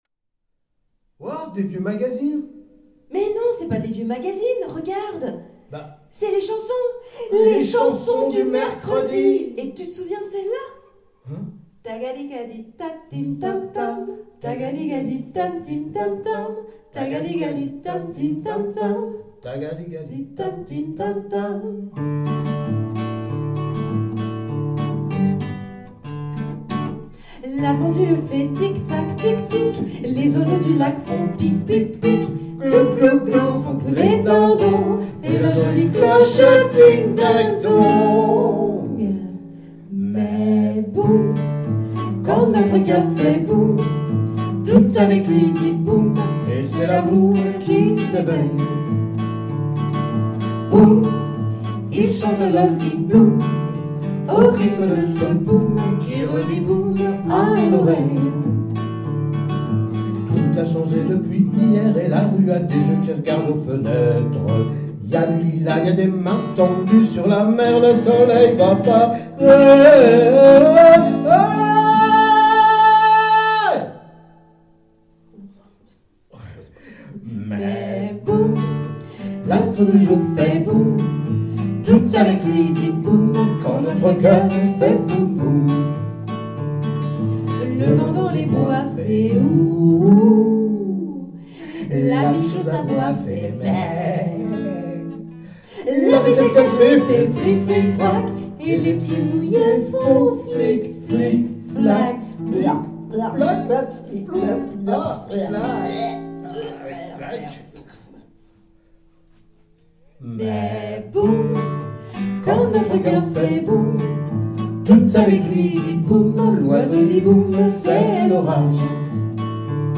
Un concert du genre cabaret qui ne manque pas de pétillant !